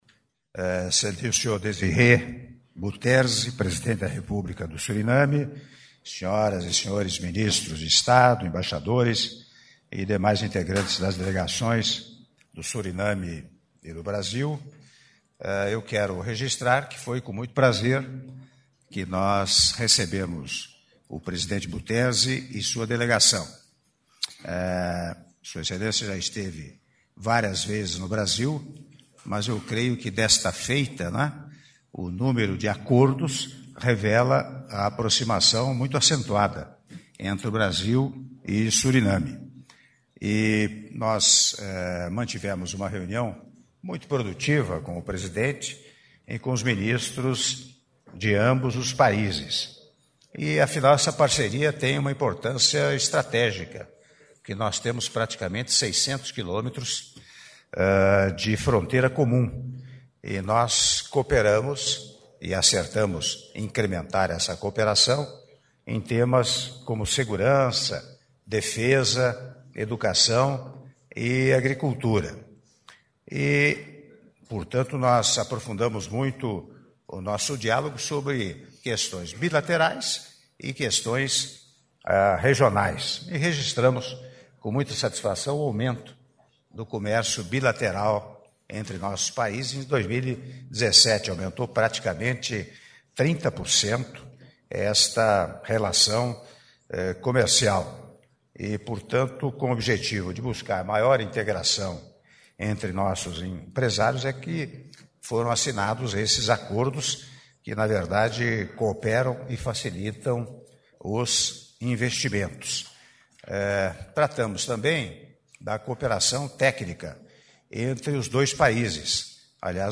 Áudio da declaração à imprensa do Presidente da República, Michel Temer, após cerimônia de assinatura de atos - Brasilia-DF - (03min58s)